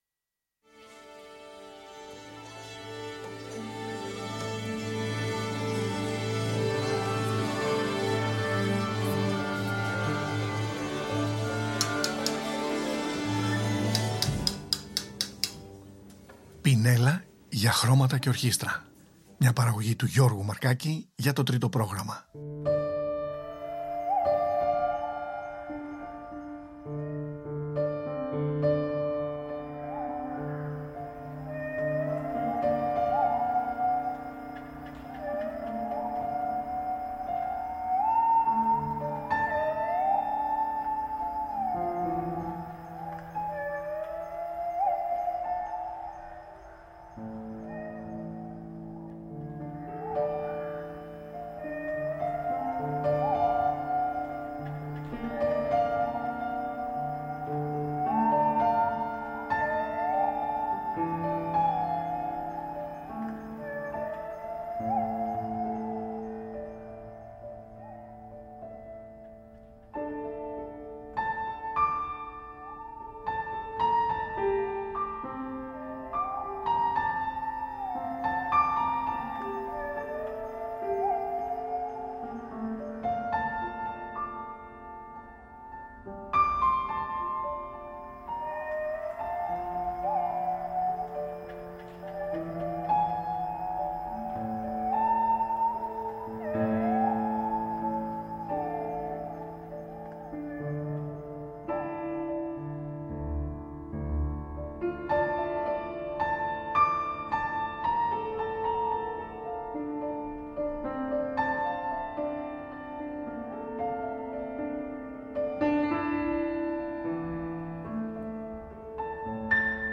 Ο Håkan Hardenberger ερμηνεύει Betsy Jolas